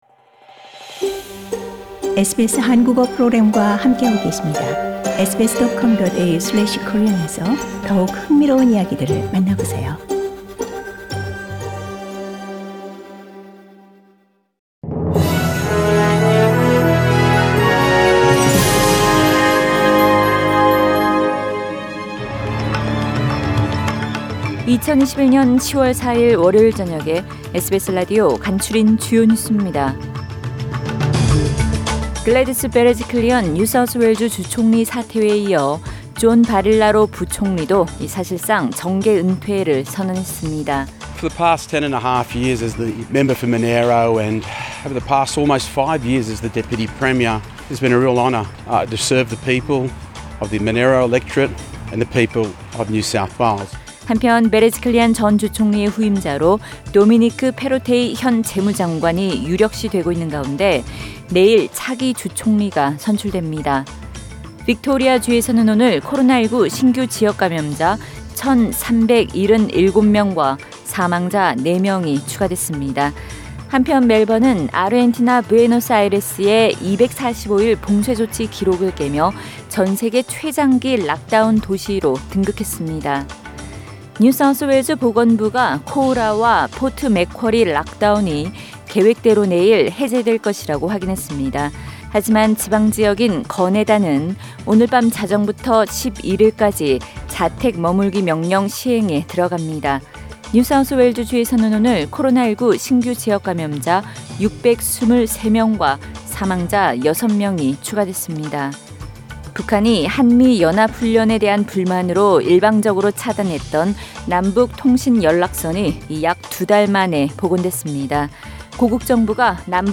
SBS News Outlines…2021년 10월 4일 저녁 주요 뉴스
2021년 10월 4일 월요일 저녁의 SBS 뉴스 아우트라인입니다.